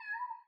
Котенок мяукнул где-то вдалеке звук